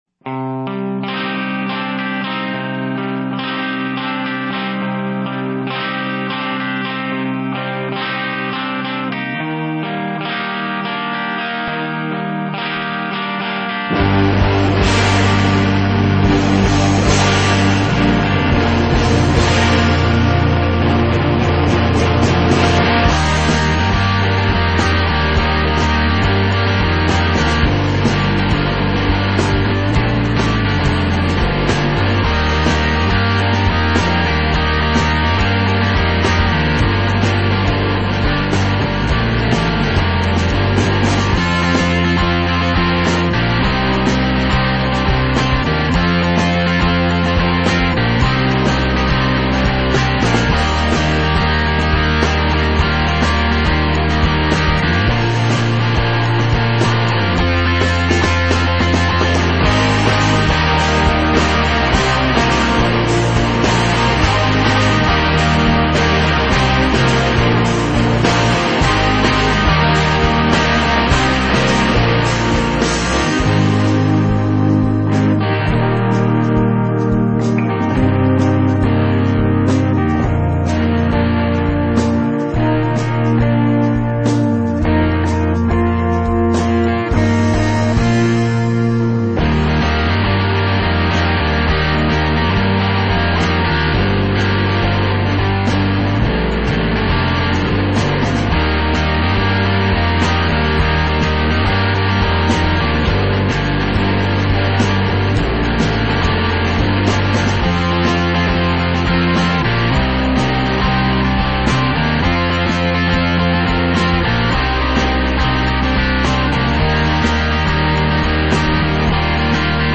punk
metal
hard rock
high energy rock and roll